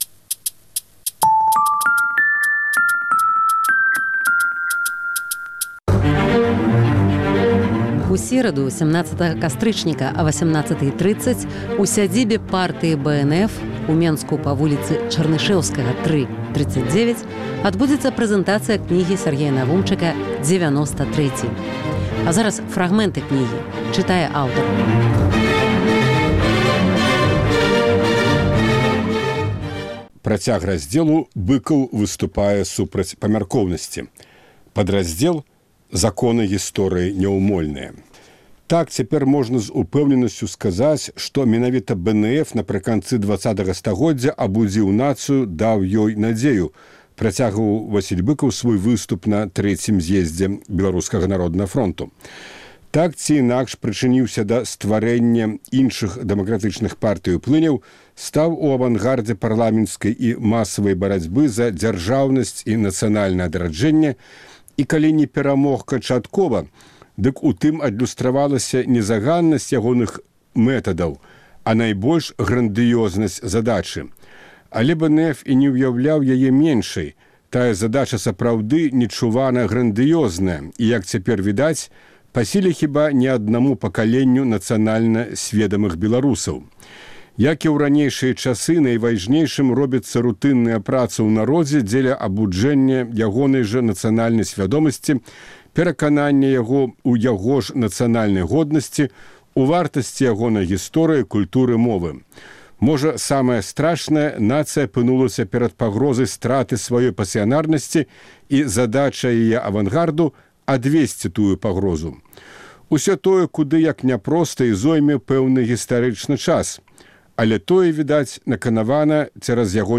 Фрагмэнты кнігі Сяргея Навумчыка "Дзевяноста трэці". Чытае аўтар.